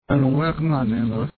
これには表音依存の傾向がみられるが、それまで石原氏は暴走老人と言われ、 意地悪な質問を繰り返し受けてきたことに対する不快感の現れと取れるかもしれず、 平均的な人々の言葉よりも明確に聞こえると思われる。